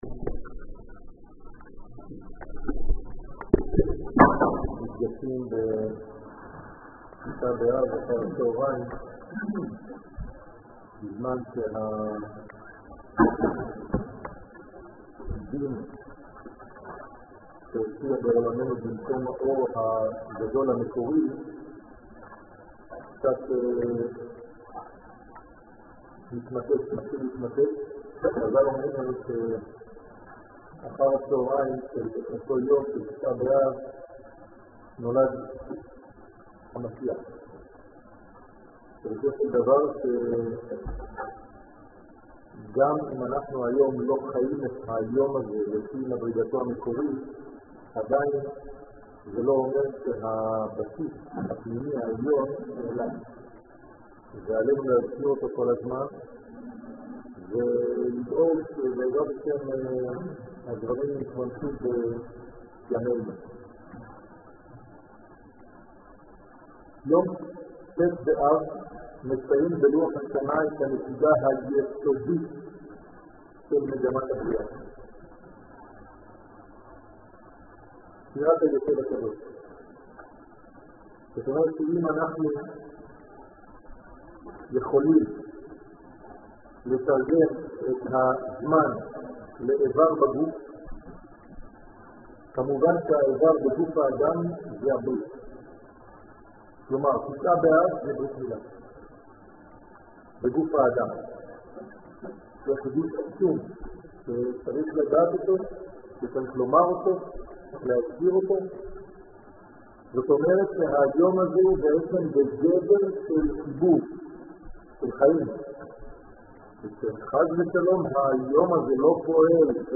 שיעור תשעה באב תשע''ג